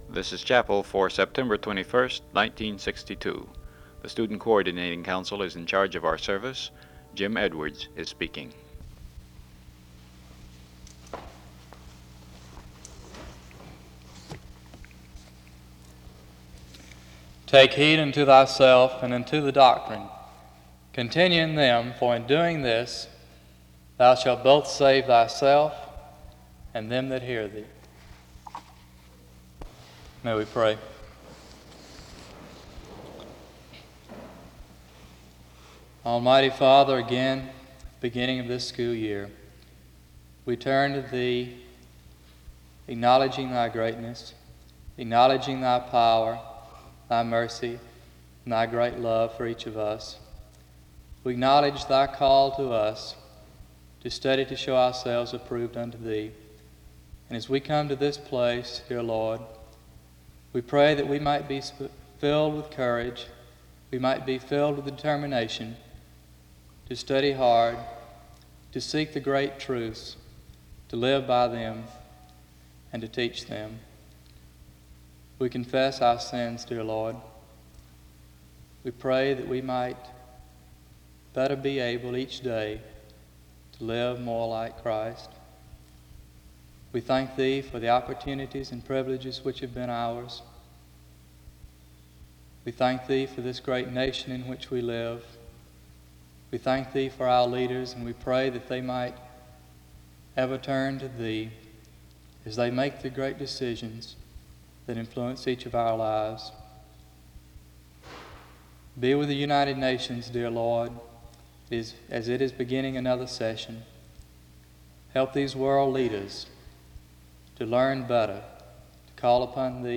This chapel service was organized by the Student Coordinating Council.